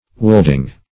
Woolding - definition of Woolding - synonyms, pronunciation, spelling from Free Dictionary
Woolding \Woold"ing\, n. (Naut.)